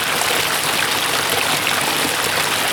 STREAM_FOUNTAIN_Texture_loop_mono.wav